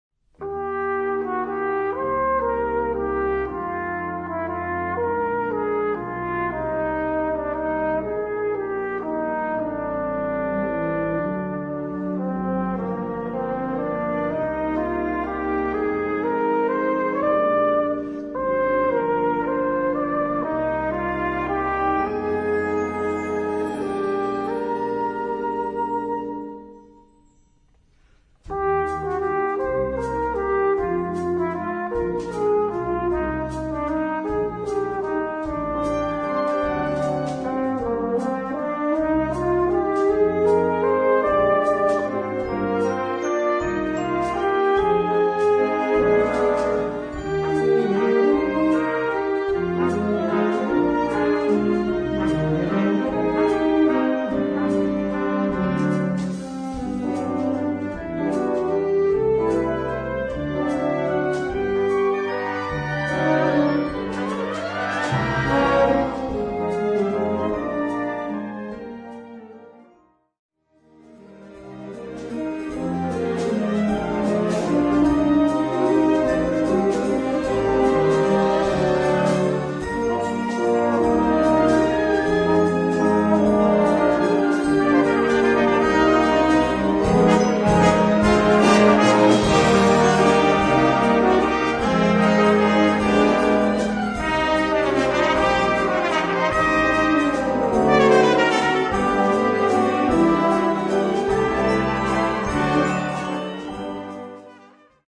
Gattung: Filmmusik
Besetzung: Blasorchester
in einer jazzy Fassung für Sinfonisches Blasorchester